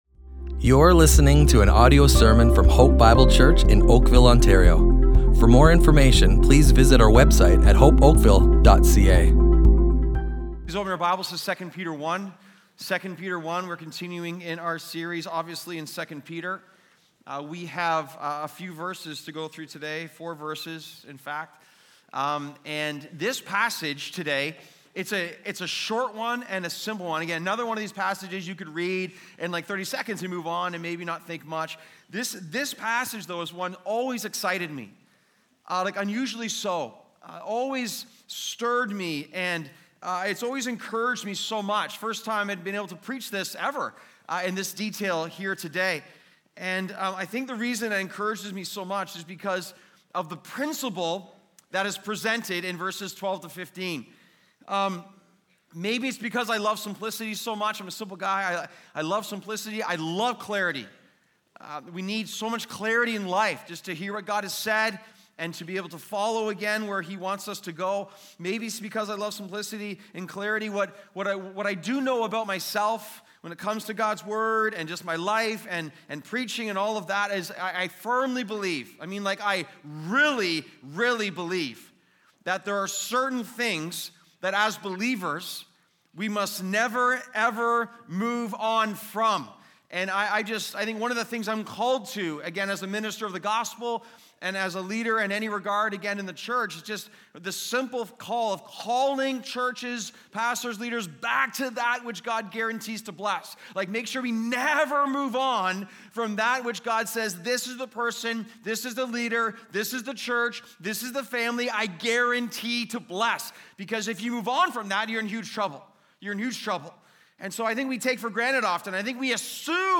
Hope Bible Church Oakville Audio Sermons Character, Corruption, and the Second Coming // Never Tire of Being Reminded!